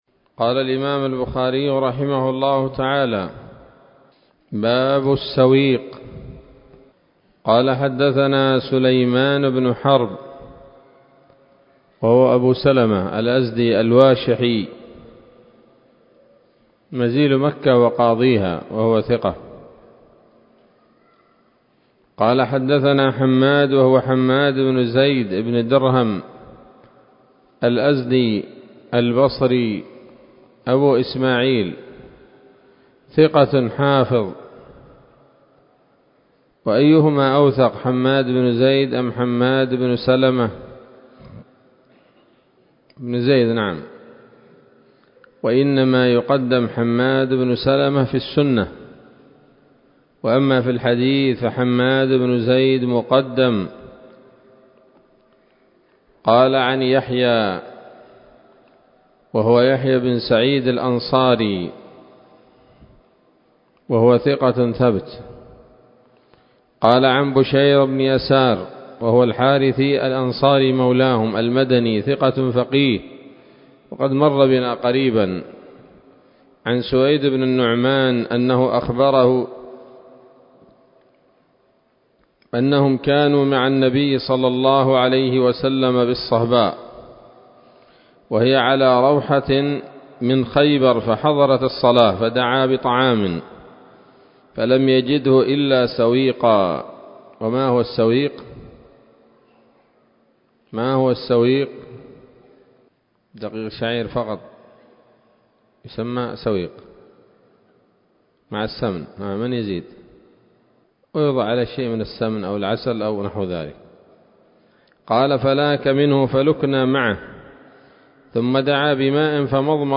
الدرس السابع من كتاب الأطعمة من صحيح الإمام البخاري